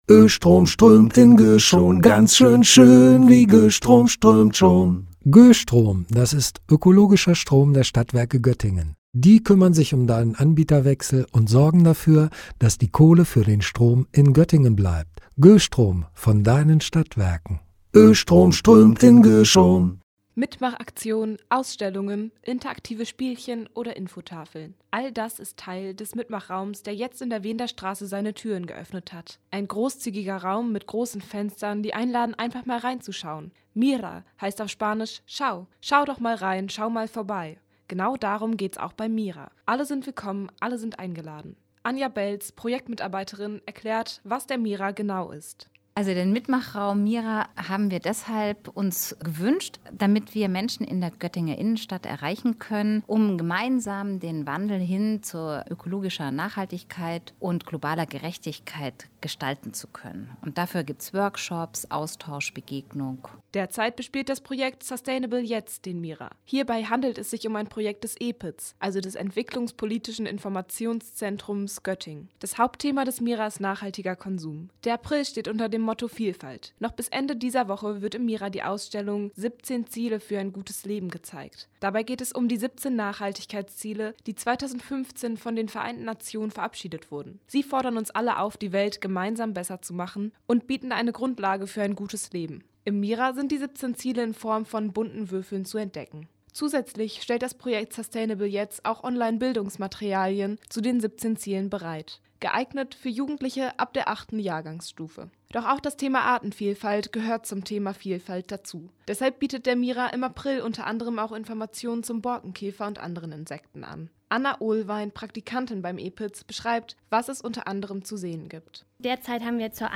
Jetzt hat er wieder geöffnet und ist in der Göttinger Fußgängerzone, direkt am Nabel zu finden. Dort gibt es viele Aktionen zu dem Projekt: „Sustainable Jetzt“, also Nachhaltigkeit jetzt.